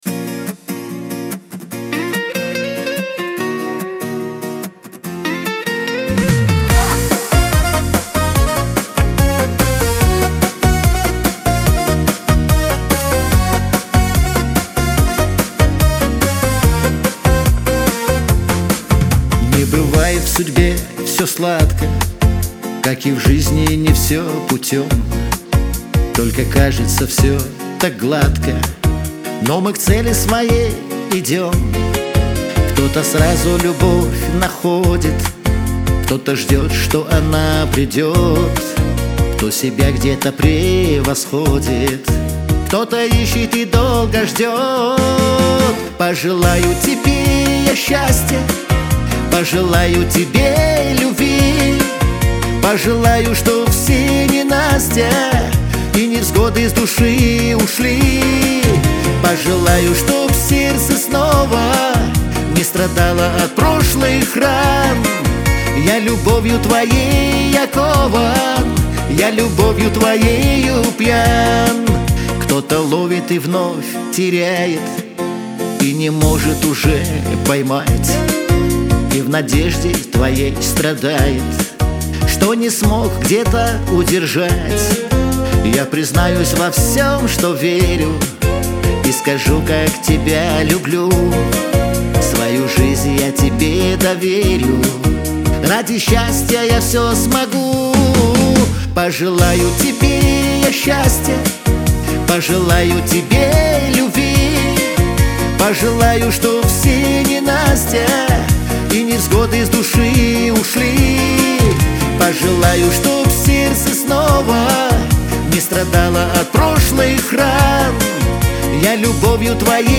pop , диско
dance